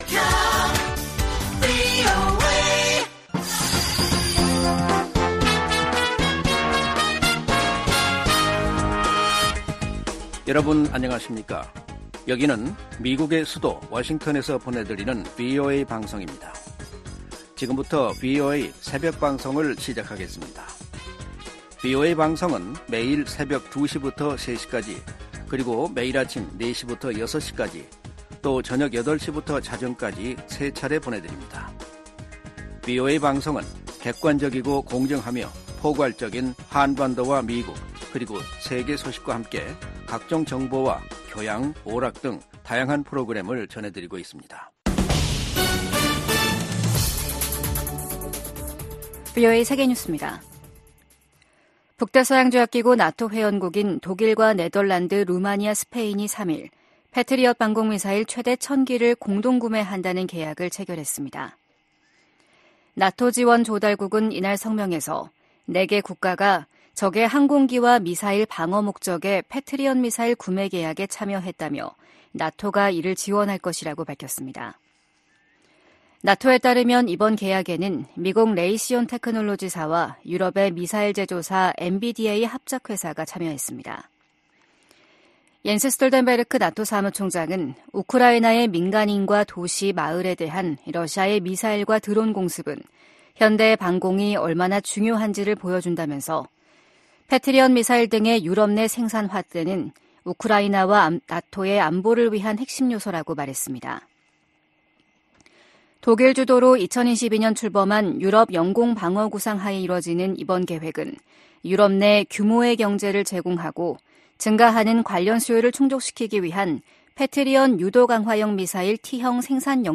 VOA 한국어 '출발 뉴스 쇼', 2024년 1월 5일 방송입니다. 미국은 북한과의 대화에 큰 기대는 않지만 여전히 환영할 것이라고 국무부 대변인이 말했습니다. 김정은 북한 국무위원장이 남북관계를 '적대적 두 국가 관계'로 선언한 이후 북한은 대남노선의 전환을 시사하는 조치들에 나섰습니다. 23일로 예정된 중국에 대한 유엔의 보편적 정례인권검토(UPR)를 앞두고 탈북민 강제북송 중단 압박이 커지고 있습니다.